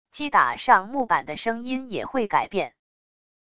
击打上木板的声音也会改变wav音频